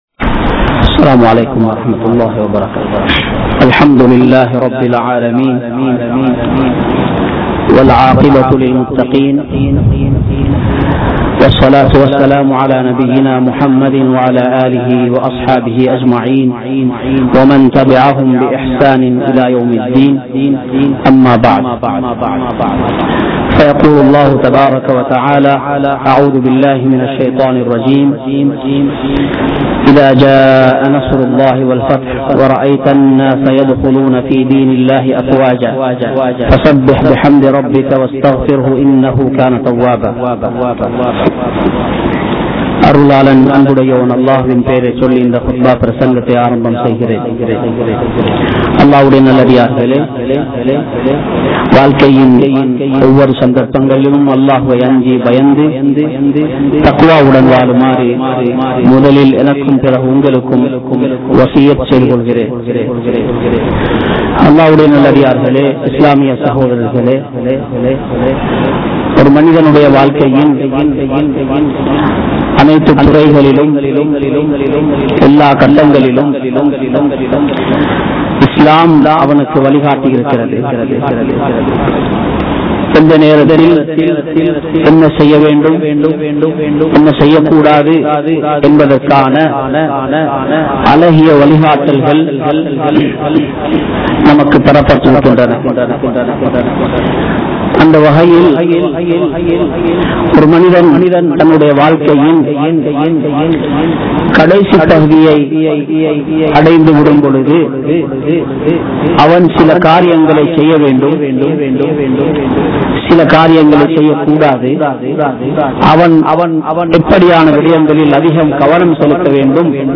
Maranam (மரணம்) | Audio Bayans | All Ceylon Muslim Youth Community | Addalaichenai
Colombo 06,Kirulapana, Thaqwa Jumua Masjith